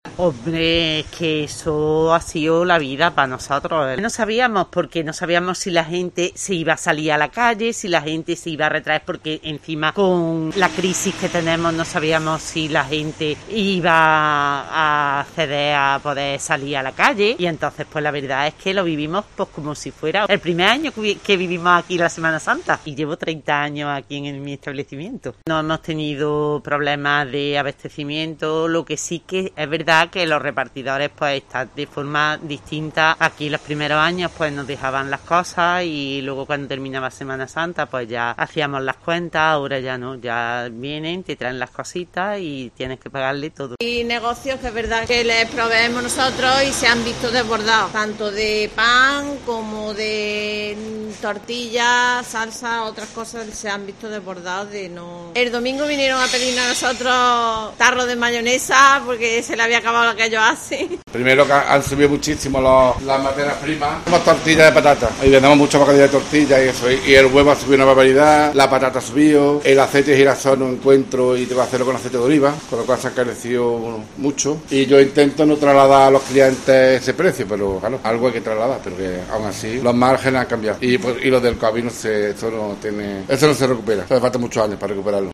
Escucha las voces de comerciantes cordobeses tras el Domingo de Ramos